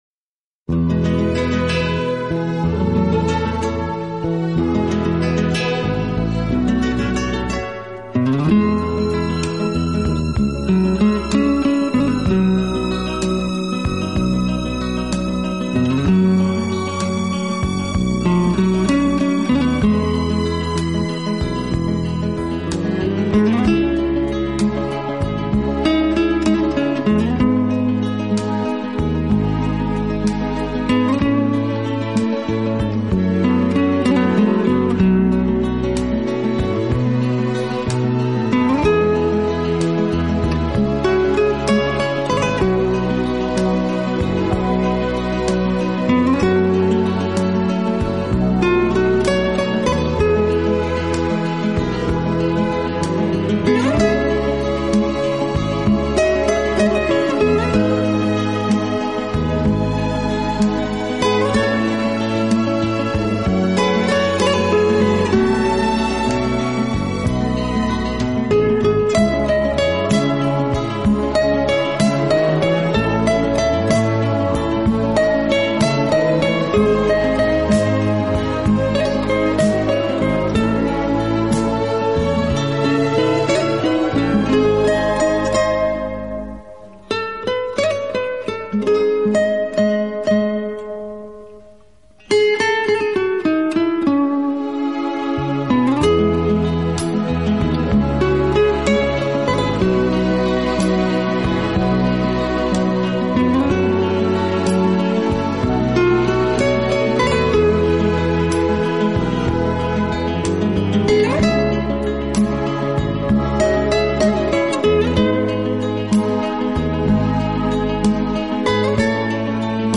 音乐类型：New Age, Acoustic